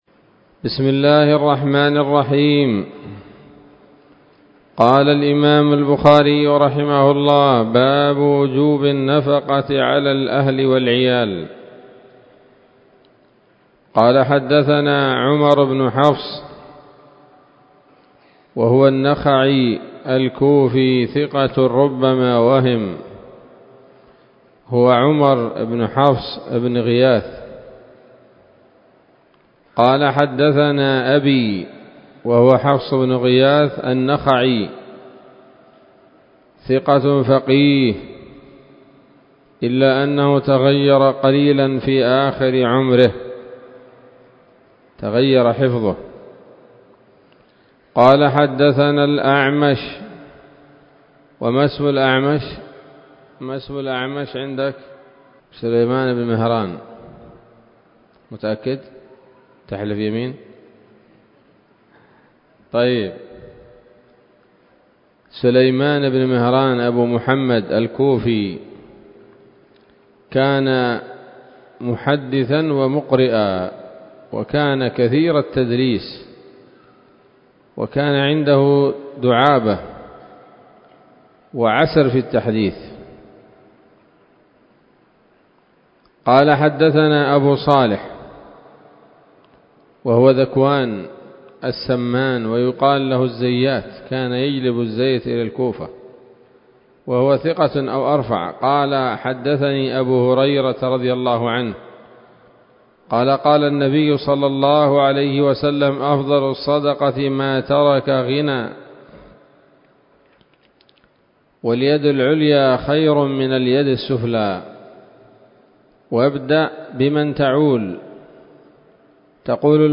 الدرس الثاني من كتاب النفقات من صحيح الإمام البخاري